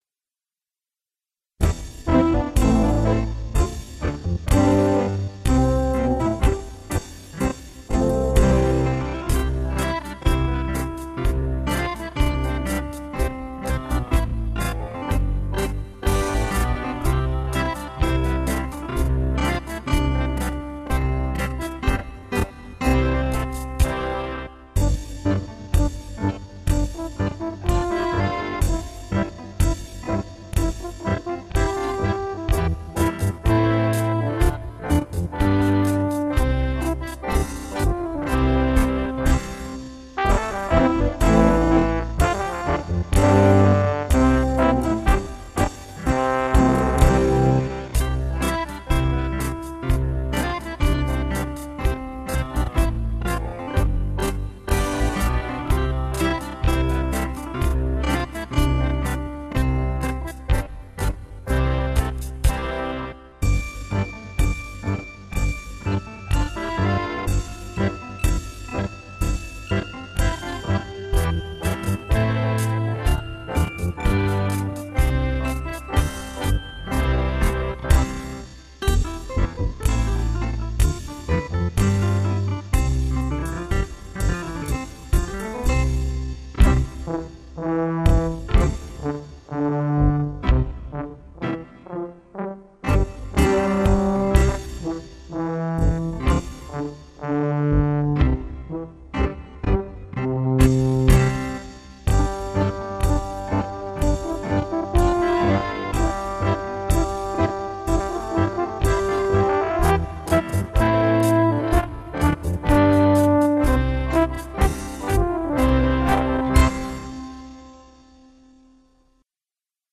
Quan seré gran (lenta)